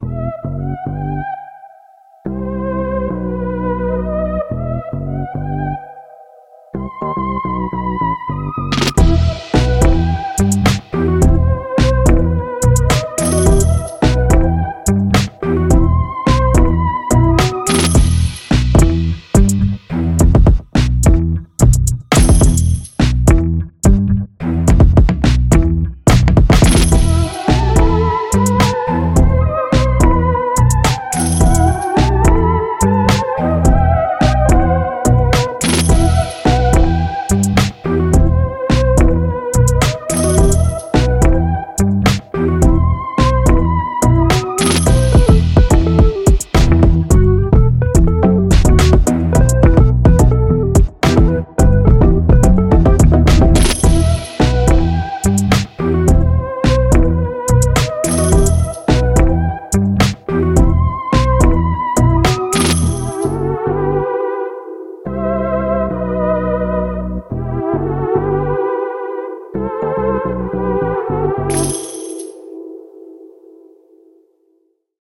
The lead harmonizes with itself in the second verse.